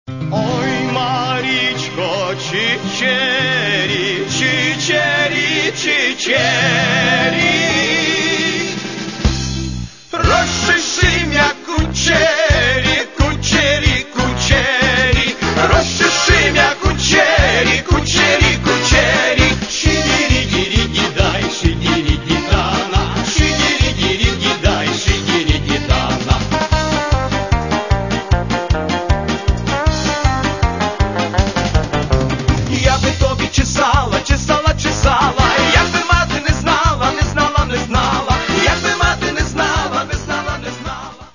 Каталог -> MP3-CD -> Эстрада
Чтобы эти самые развлечения происходили весело и живенько.